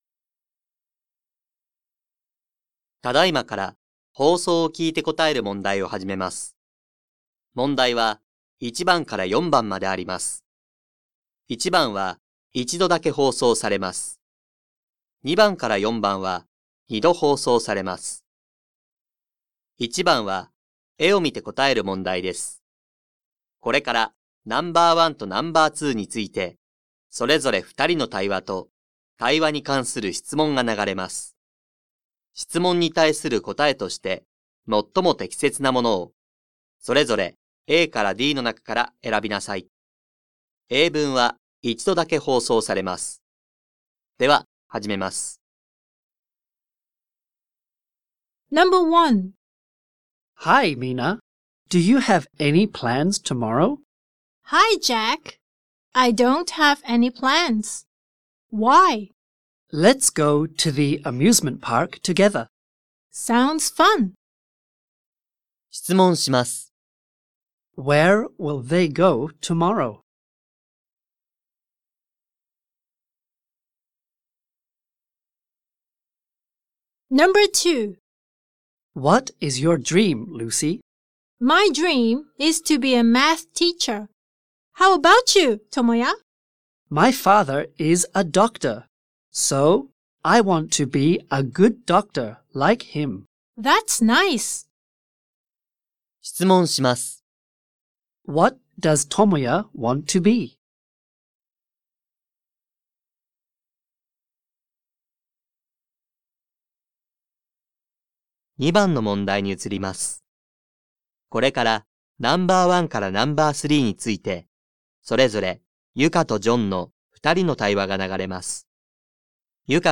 2025年度３年３号英語のリスニングテストの音声